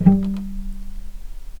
Strings / cello / pizz /
vc_pz-G3-pp.AIF